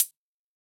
UHH_ElectroHatB_Hit-33.wav